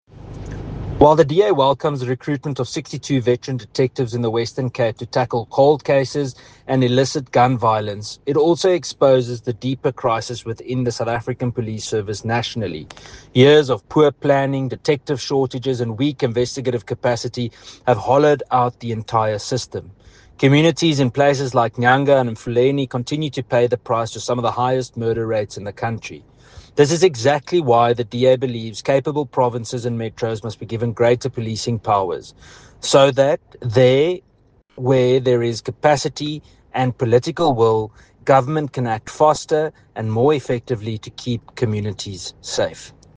here a soundbite in English by Ian Cameron MP, DA Deputy Spokesperson on Police.
Ian-Cameron-MP-DA-Deputy-Spox-on-Police.mp3